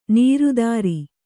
♪ nīru dāri